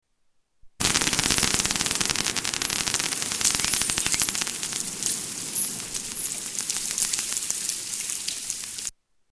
Bottlenose dolphin echolocation
Category: Animals/Nature   Right: Personal